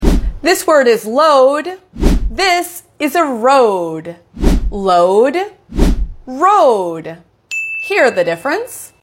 Load vs Road | Hear the Difference? (American English)